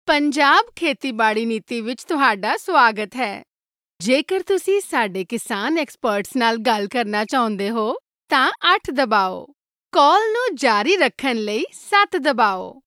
Professional female voiceover talent